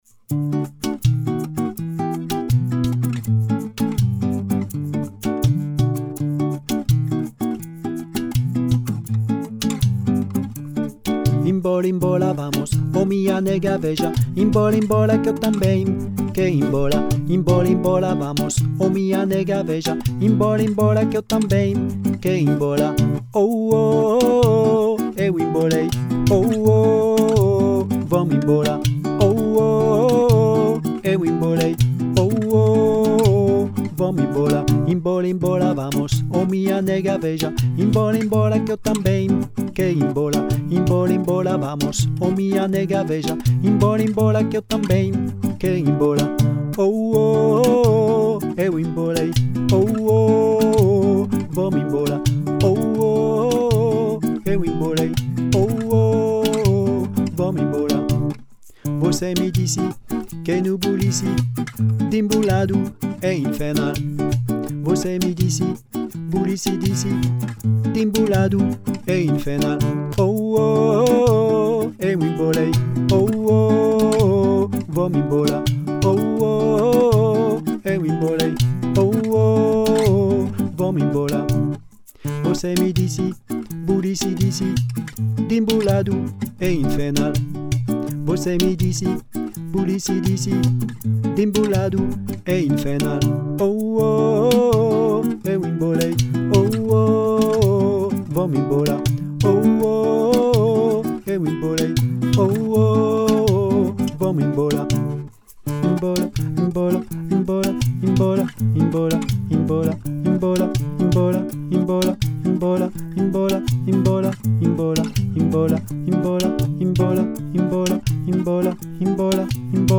Voix 2